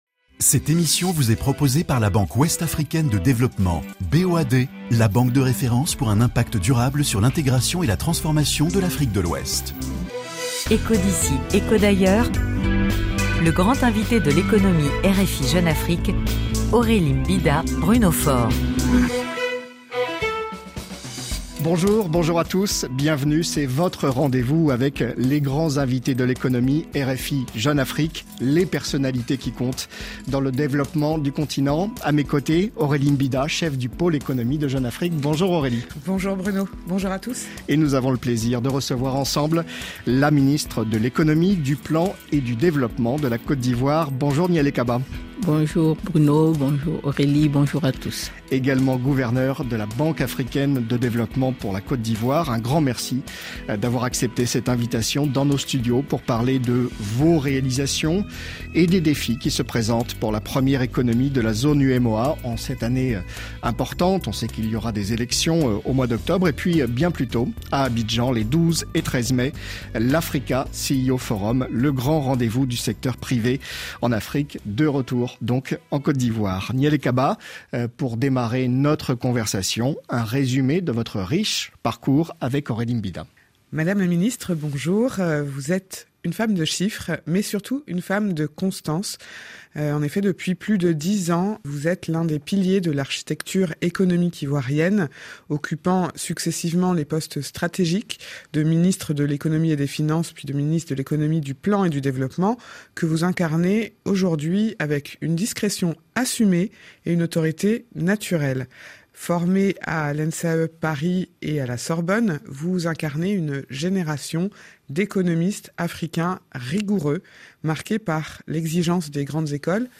Pour cet avant-dernier numéro de 2024, nous vous proposons un échange original et stimulant entre 5 jeunes journalistes africains spécialisés dans les questions économiques. Basés à Dakar, Conakry, Abidjan, Cotonou et Kinshasa, ils commentent l'actualité dans leur pays, sur le continent et ailleurs dans le monde.